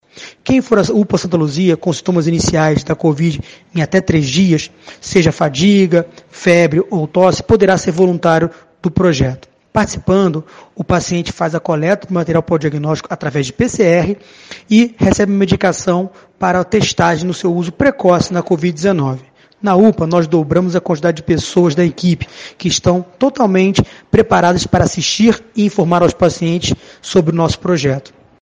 infectologista